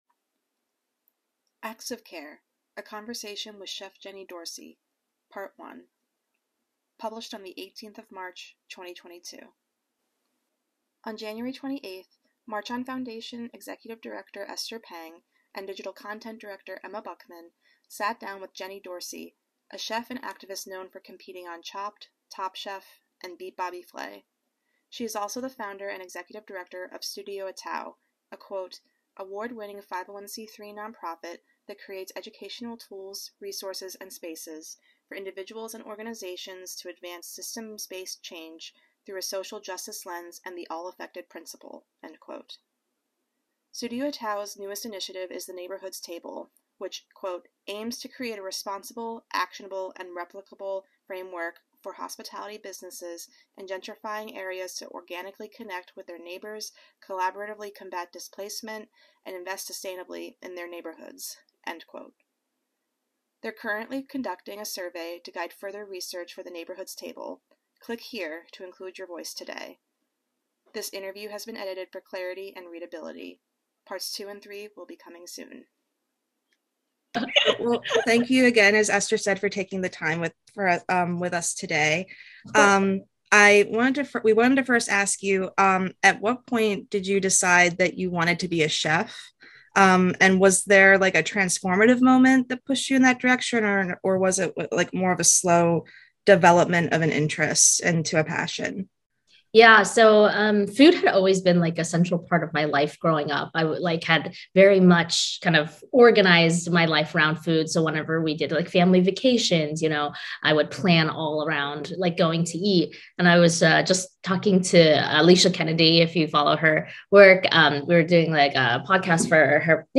This interview has been edited for clarity and readability.